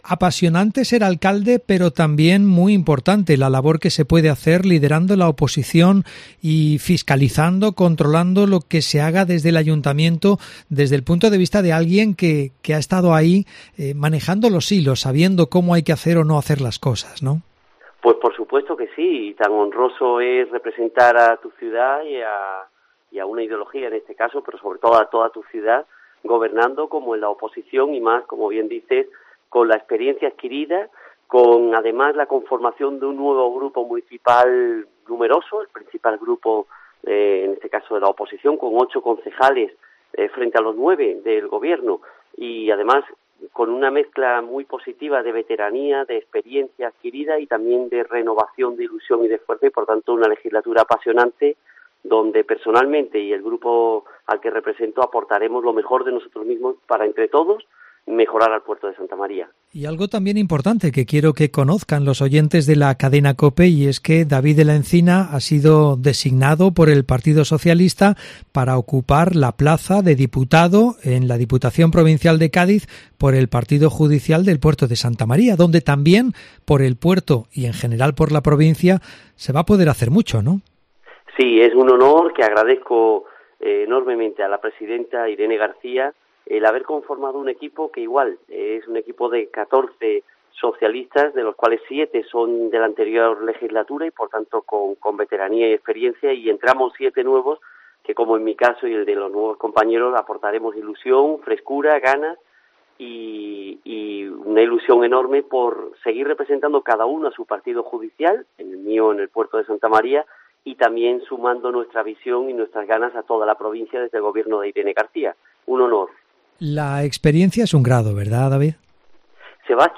Entrevista David de la Encina, exalcalde de El Puerto